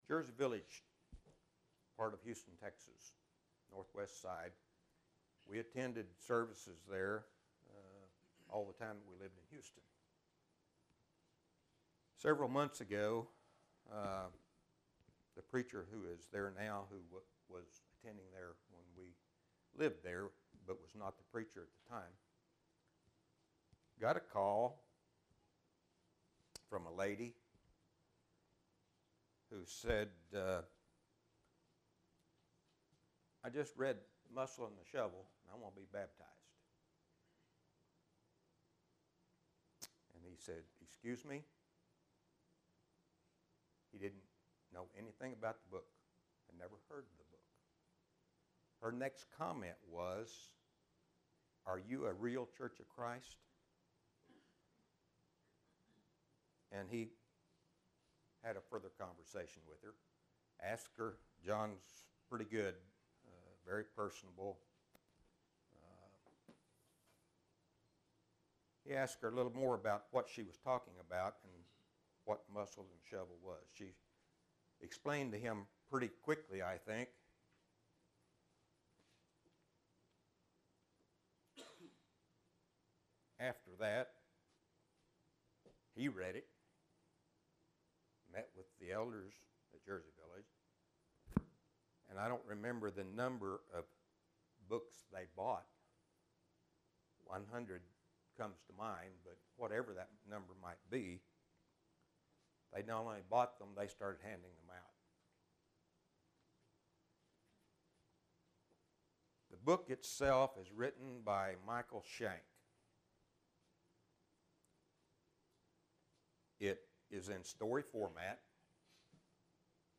Sharing Your Faith Service Type: Adult Class Preacher